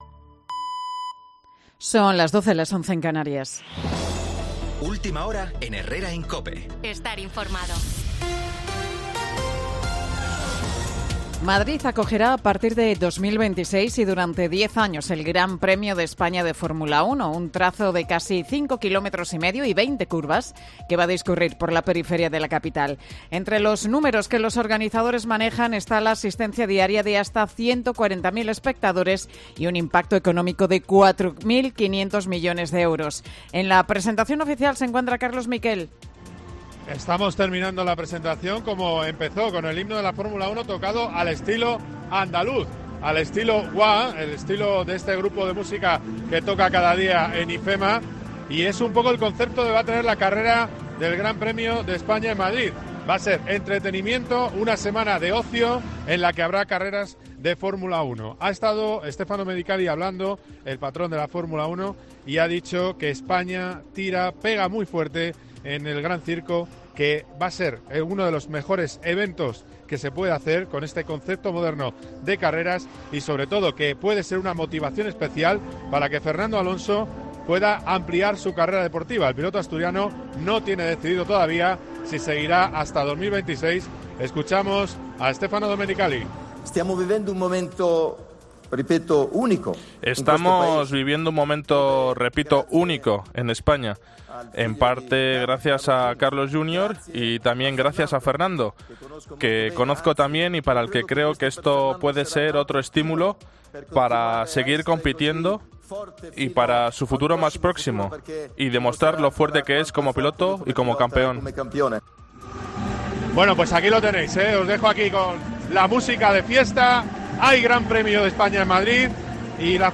Boletín de Noticias de COPE del 23 de enero del 2024 a las 12 horas